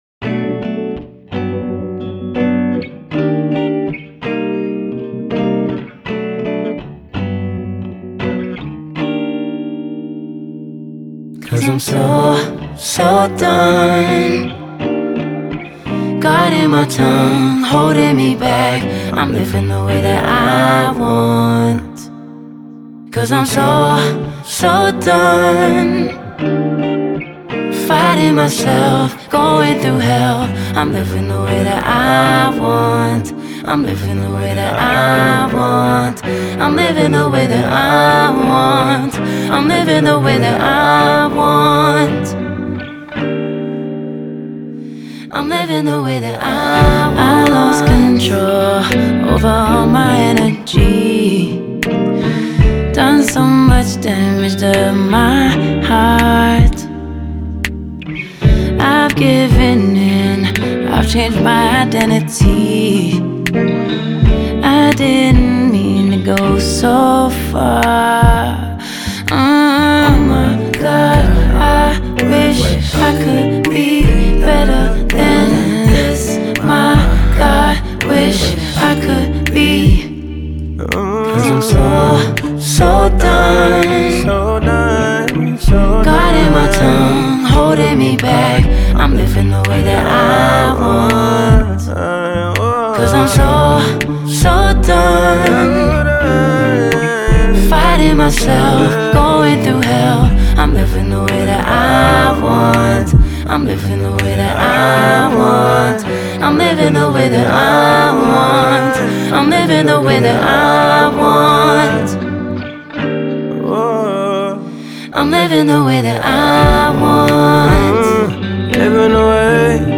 soulful single